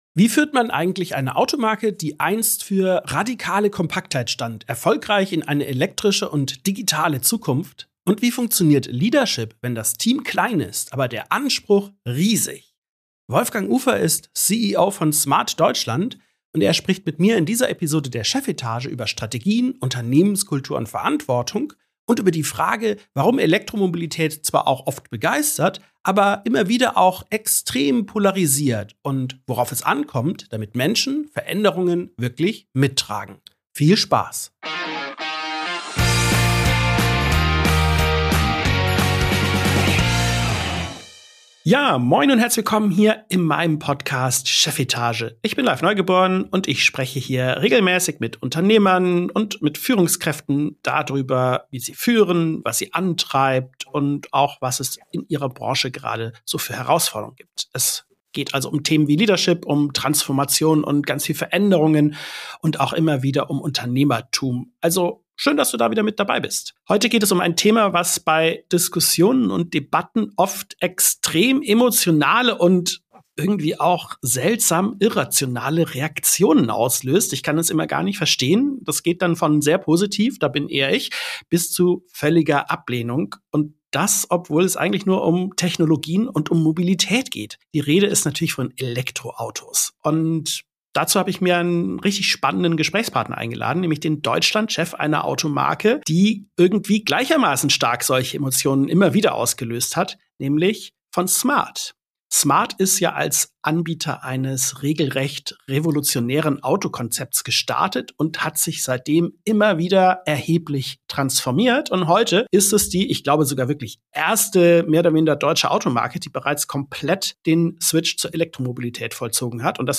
CEOs, Unternehmer und Führungskräfte im Gespräch Podcast